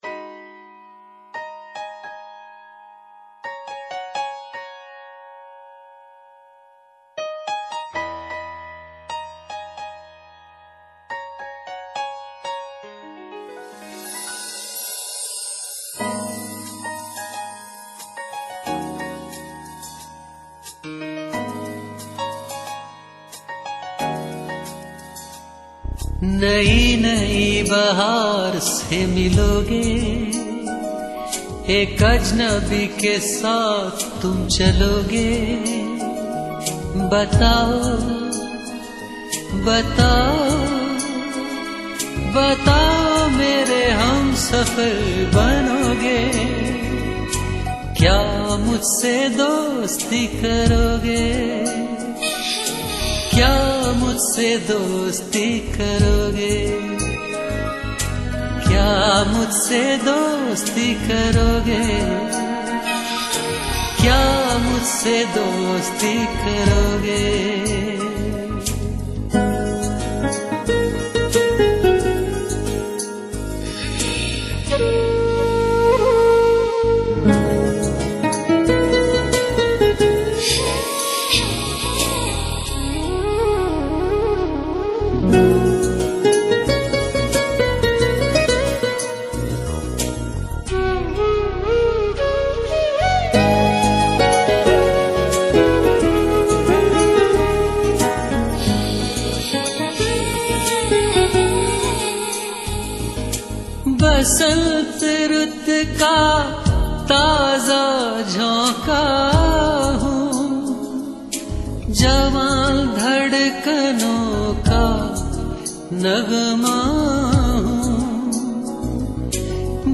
Ghazals